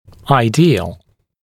[aɪ’diːəl][ай’ди:эл]идеальный, совершенный